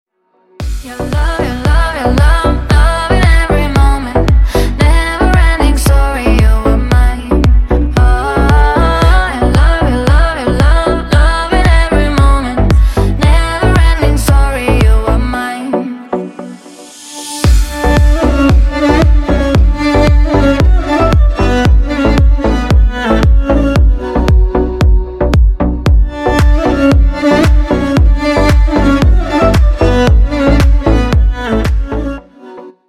поп
громкие
восточные мотивы
dance
красивый женский вокал
house